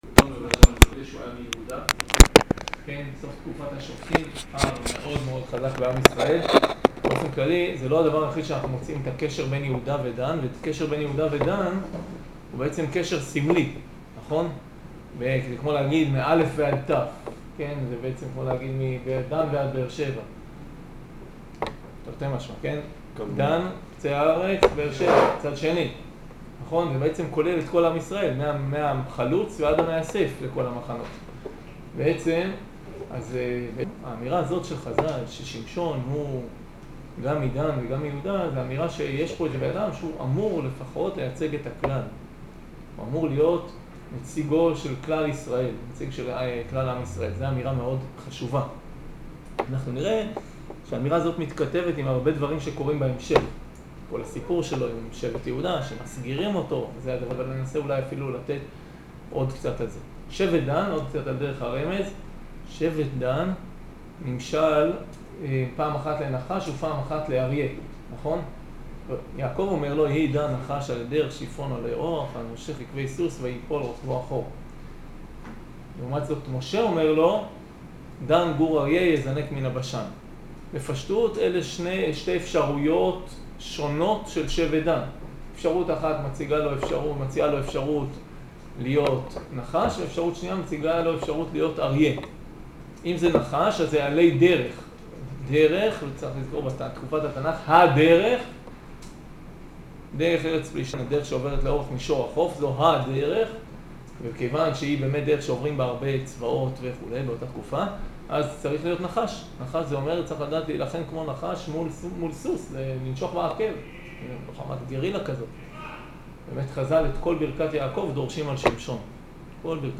שיעור שמשון